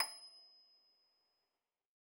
53l-pno27-D6.wav